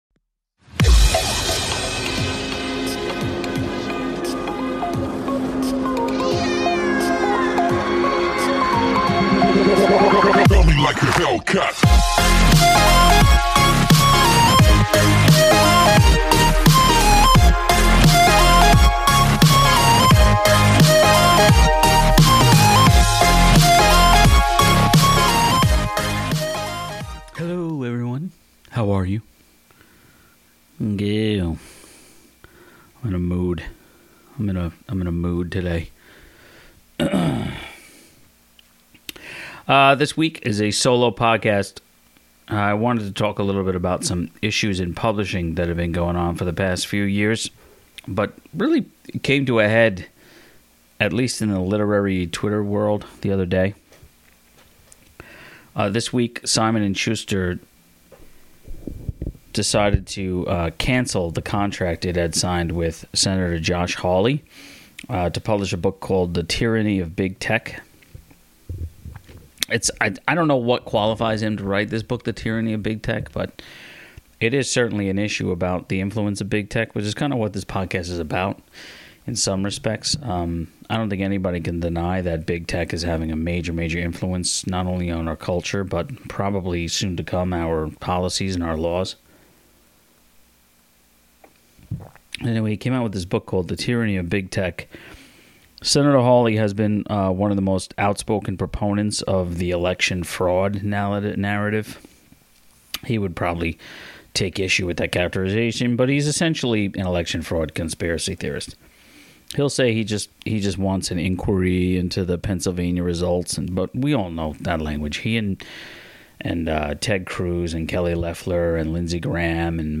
This is a solo podcast where I discuss a trend in publishing to include a "morality clause" that would allow publishers to nullify a contract if the author hits any of the myriad tripwires that could cause public "condemnation," "hatred," or "ridicule" and hurt sales.